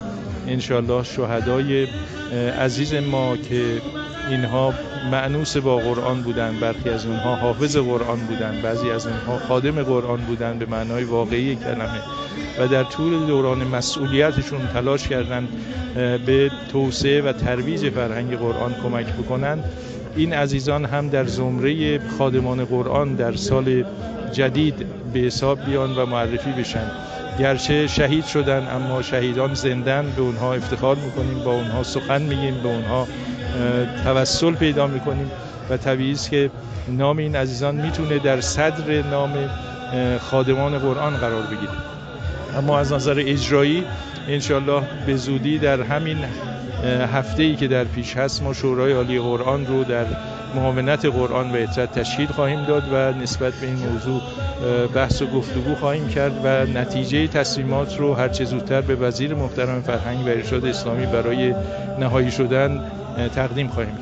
در گفت‌وگو با ایکنا مطرح شد